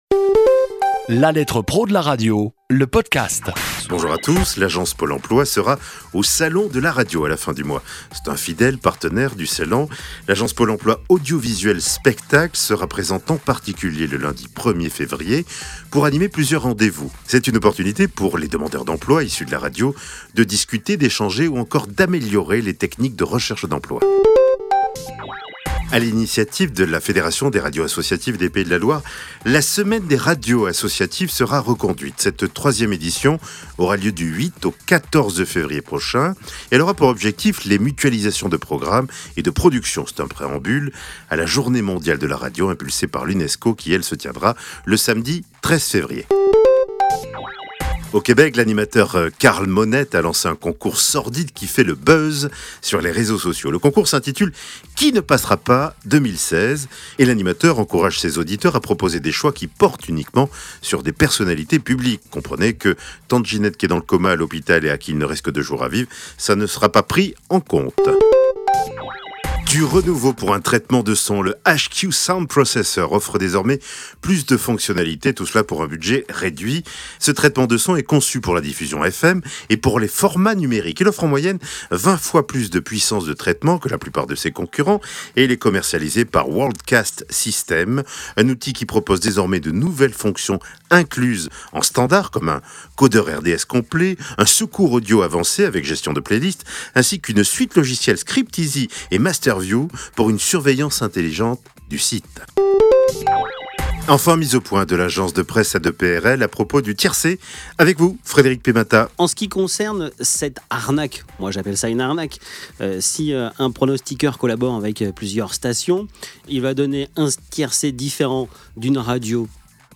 Un condensé de l'actualité de la semaine traitée ici ou dans le magazine. Cette capsule propose également des interviews exclusives de professionnels de la radio.